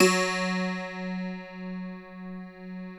53q-pno08-F1.wav